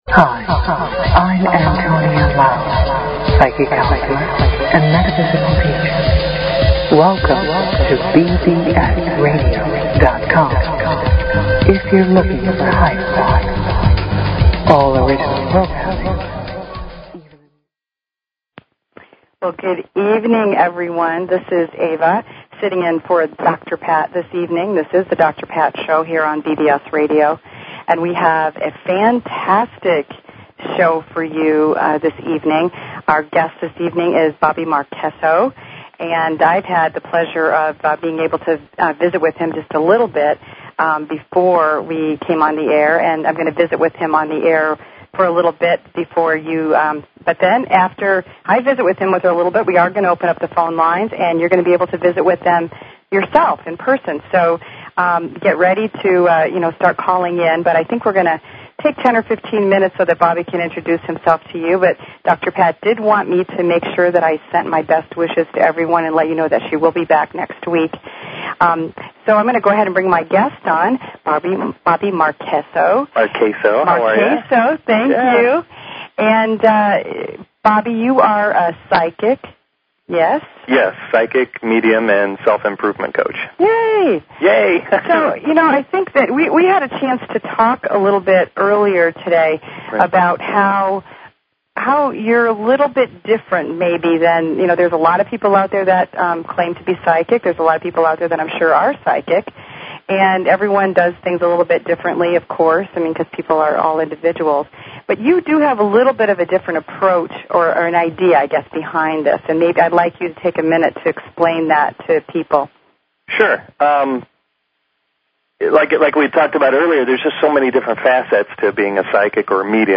Talk Show Episode
Psychic Medium, Self Improvement Coach